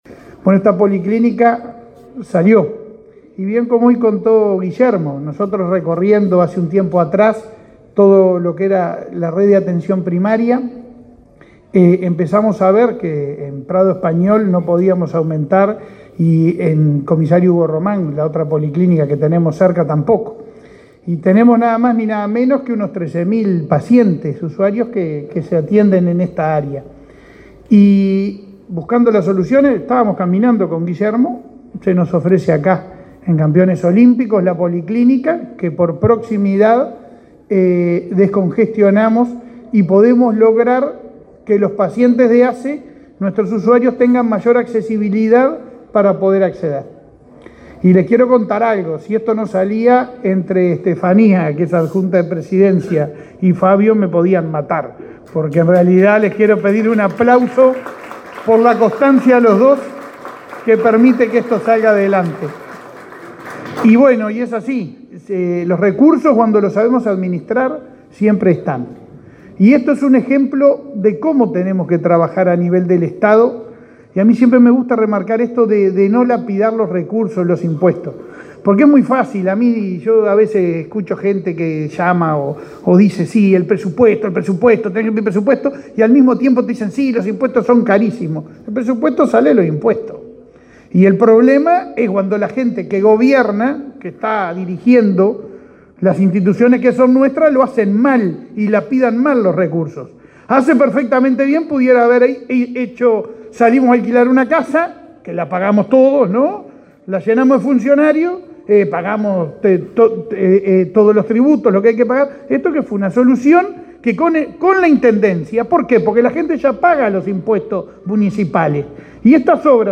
Palabra de autoridades en inauguración de policlínica en Florida